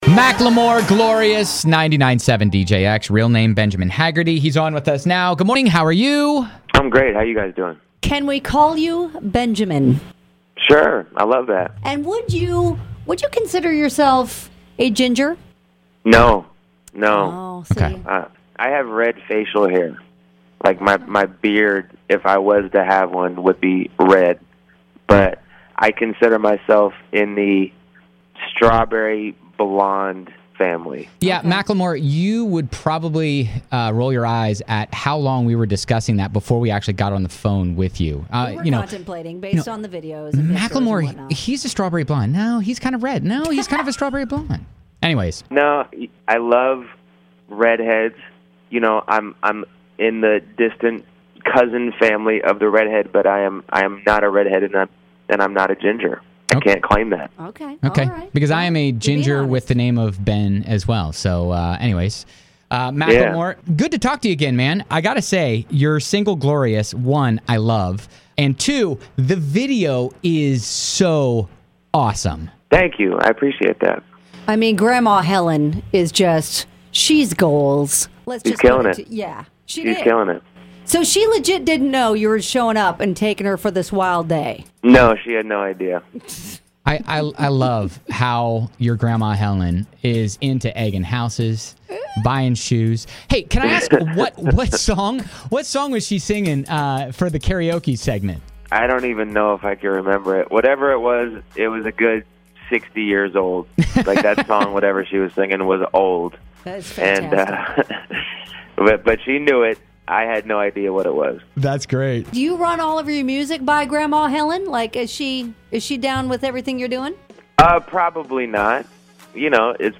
MACKLEMORE CALLED THE SHOW
clucks like a chicken :)